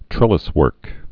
(trĕlĭs-wûrk)